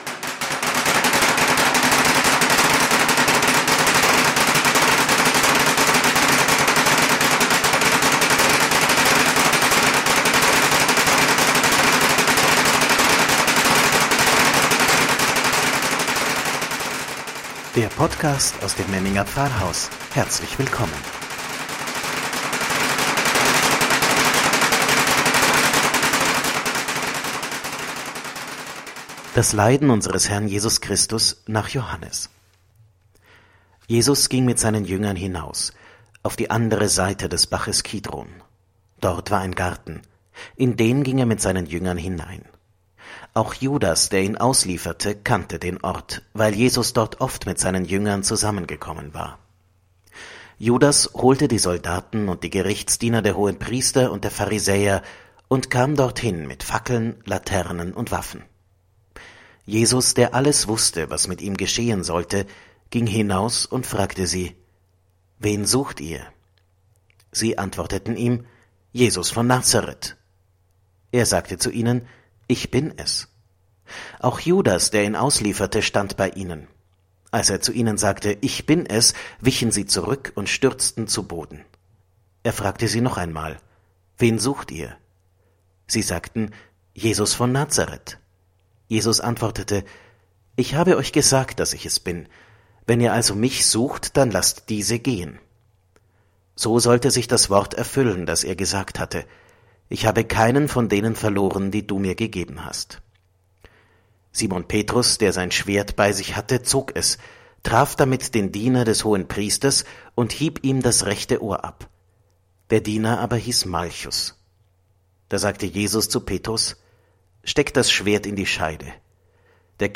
„Wort zum Sonntag“ aus dem Memminger Pfarrhaus – Podcast zu Karfreitag